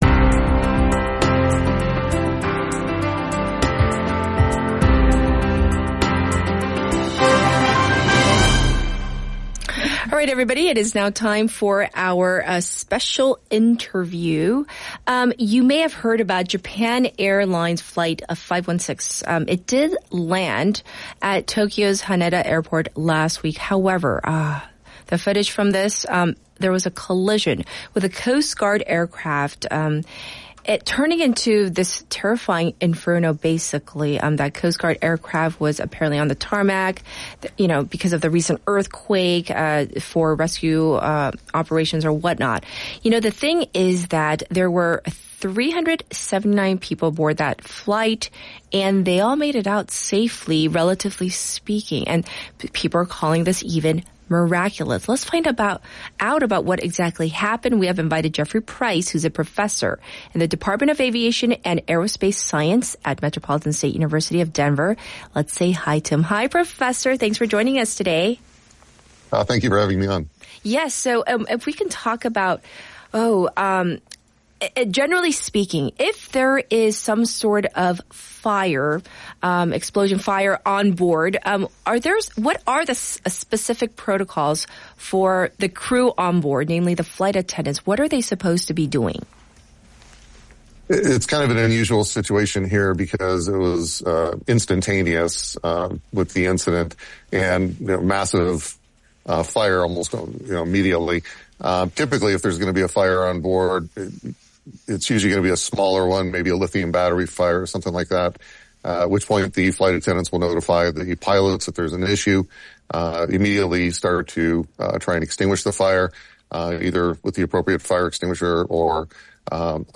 Japan Airlines Flight 516 Evacuation